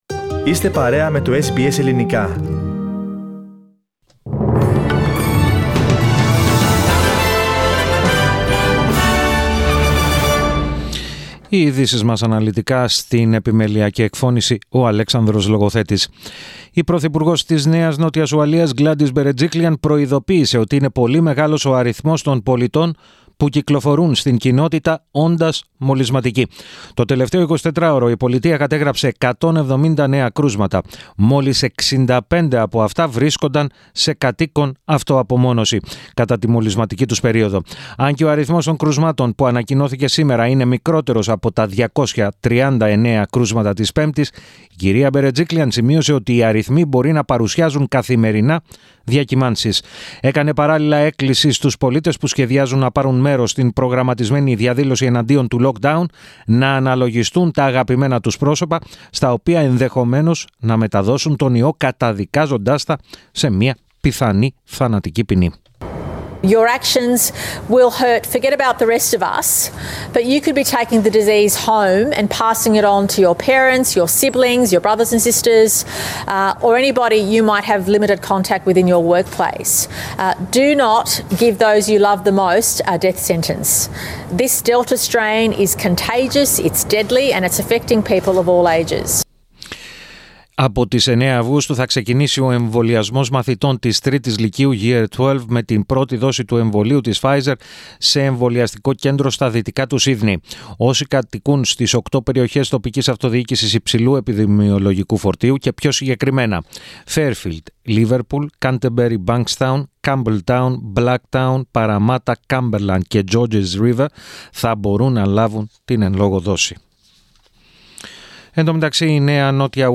Το αναλυτικό δελτίο στις 16:00